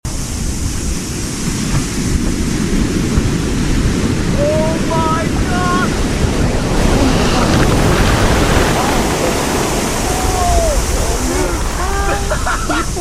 Tsunami Hits Resort Beachfront in sound effects free download